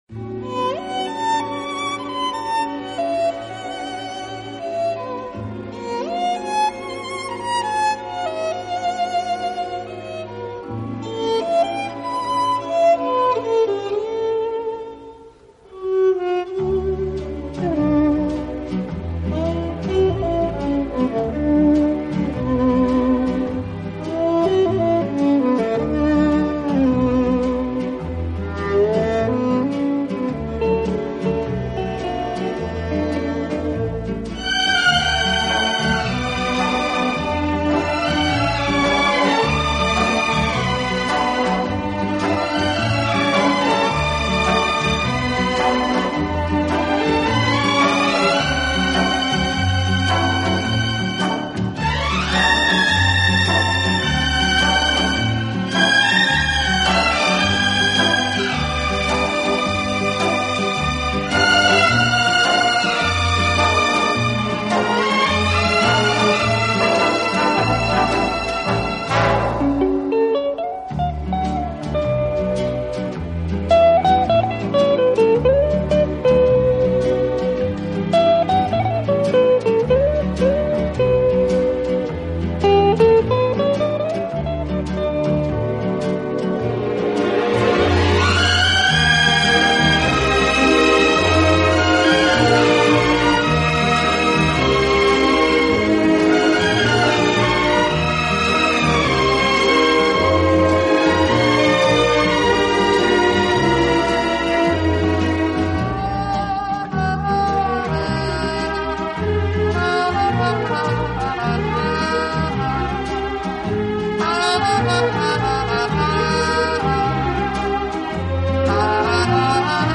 【轻音乐专辑】
乐队以弦乐为中坚，演奏时音乐的处理细腻流畅，恰似一叶轻舟，随波荡